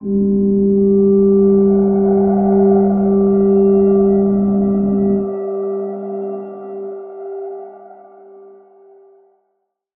G_Crystal-G4-mf.wav